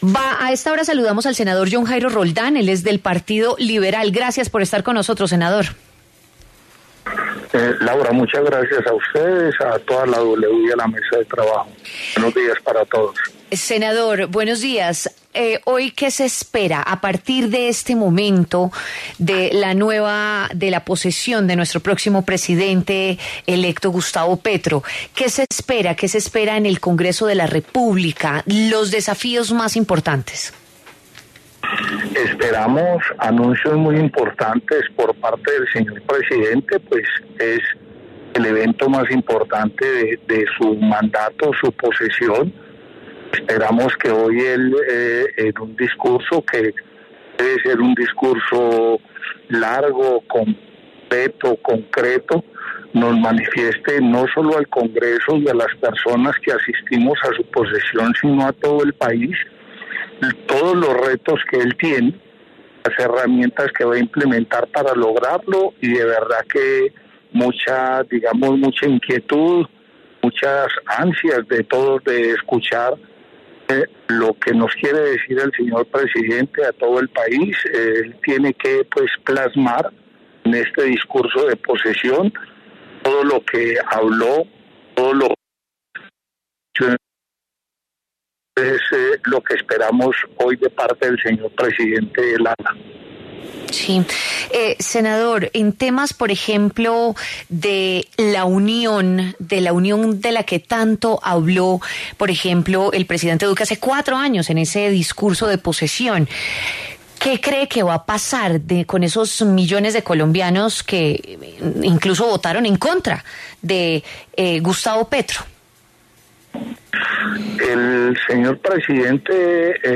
John Jairo Roldán, senador del Partido Liberal, habló en W Fin de Semana sobre la posesión de Gustavo Petro este 7 de agosto.
En el encabezado, escuche la entrevista completa con John Jairo Roldán, senador del Partido Liberal.